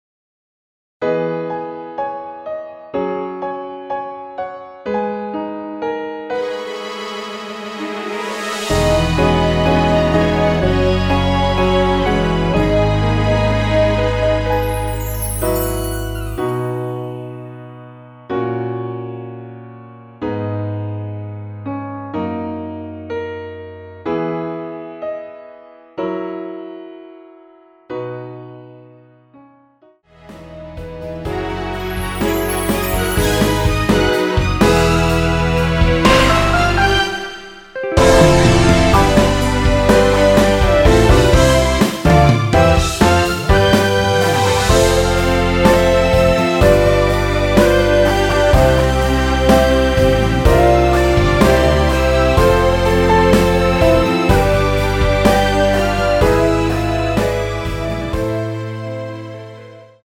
원키에서(-7)내린 MR입니다.
남성분이 부르실수 있는 키로 제작 하였습니다.(미리듣기 참조)
앞부분30초, 뒷부분30초씩 편집해서 올려 드리고 있습니다.
중간에 음이 끈어지고 다시 나오는 이유는